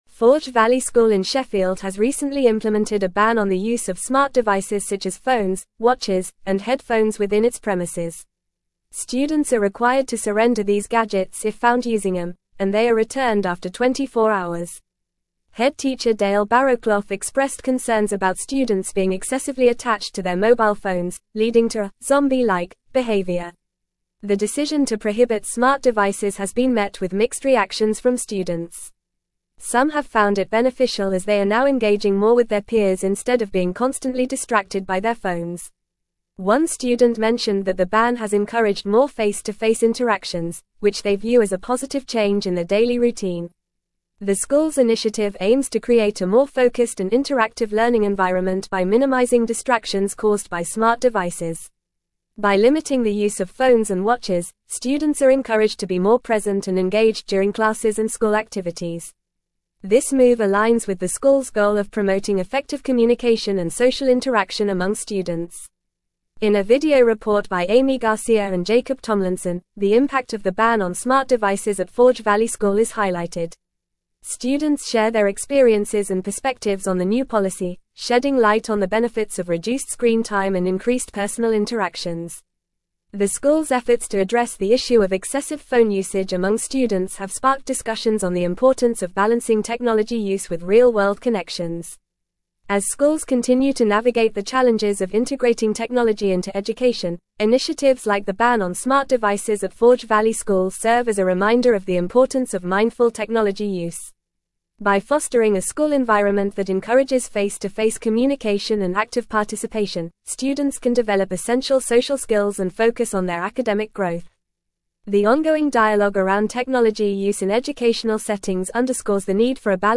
Fast
English-Newsroom-Advanced-FAST-Reading-Forge-Valley-School-Implements-Ban-on-Smart-Devices.mp3